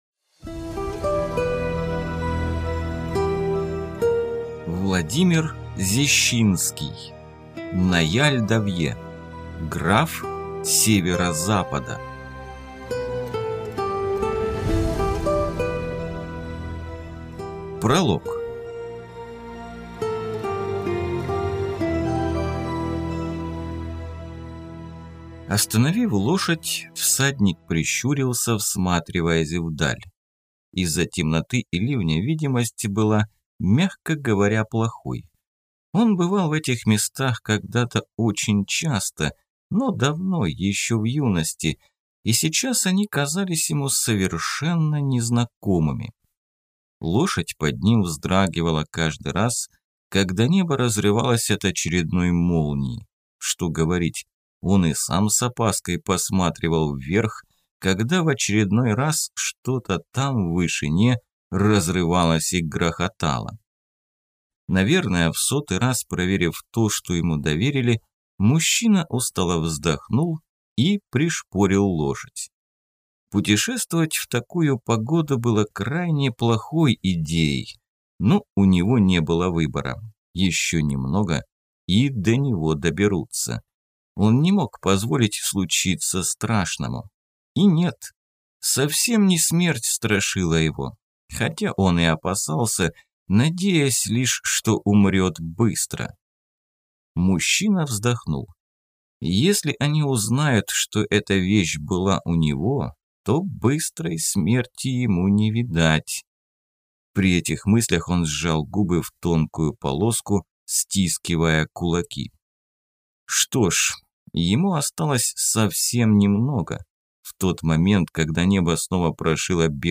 Аудиокнига Наяль Давье. Граф северо-запада | Библиотека аудиокниг